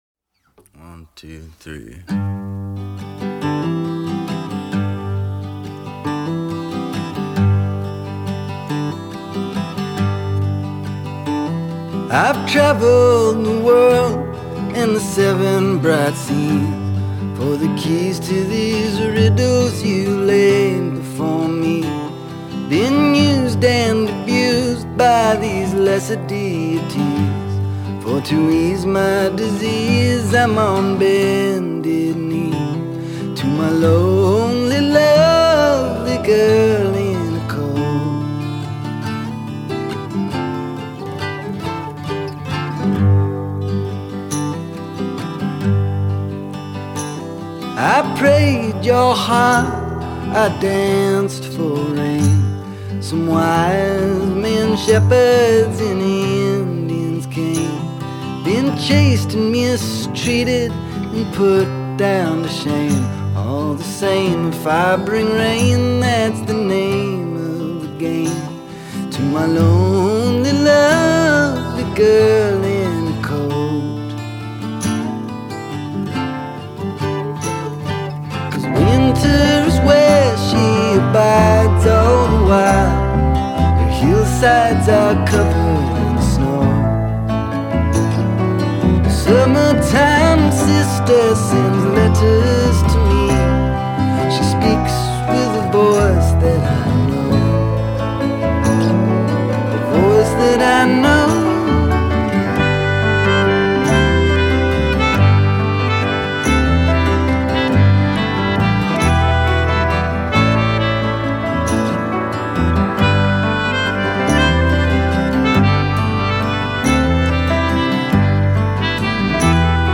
Its a bit of a retro hazy throwback style